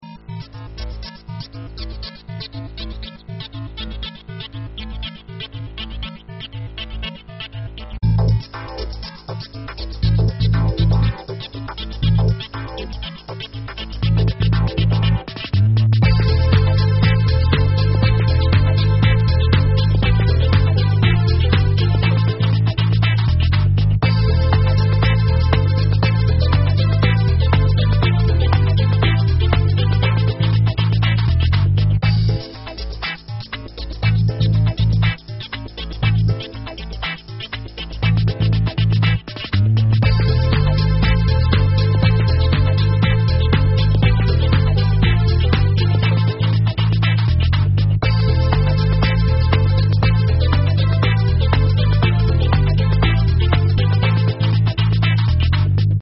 Genre : regey